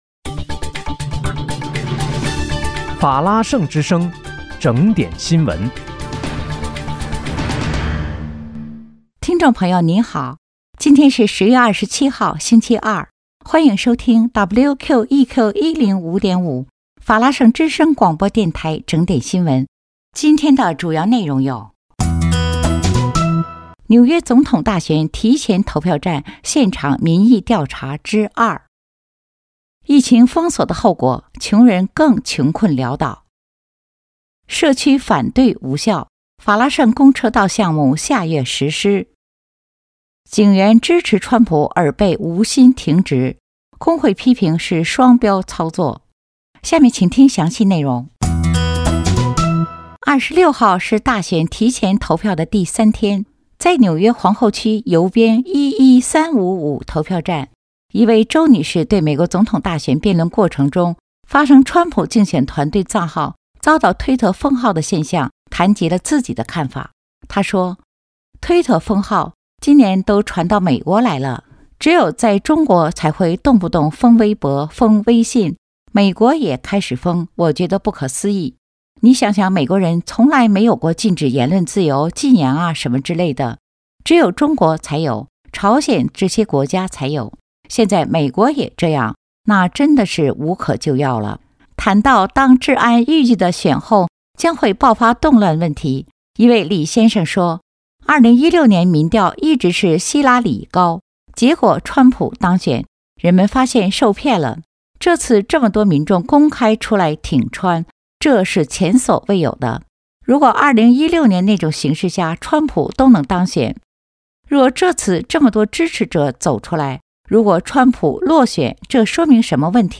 10月27日（星期二）纽约整点新闻